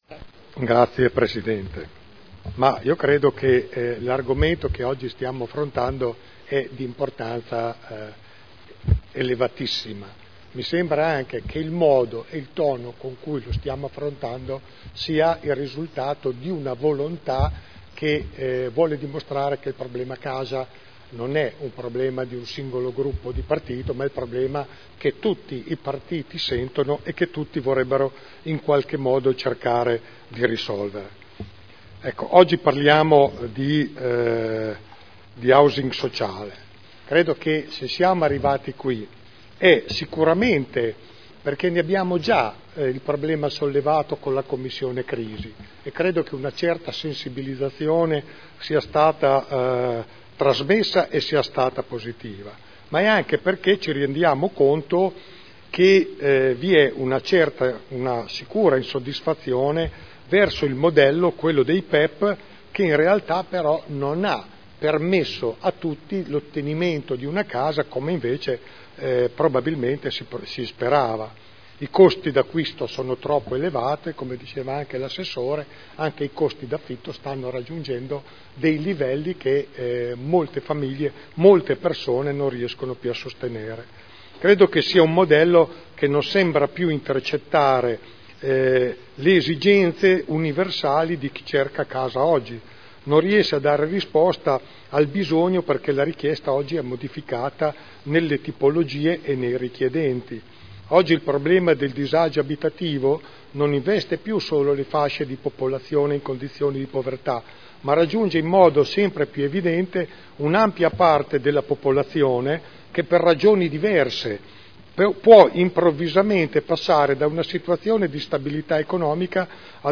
Giancarlo Pellacani — Sito Audio Consiglio Comunale
Seduta del 09/01/2012. Dibattito sui due ordini del giorno su Social Housing e riqualificazioni urbana.